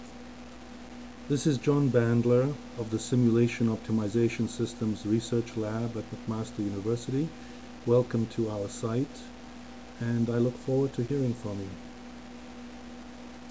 Greeting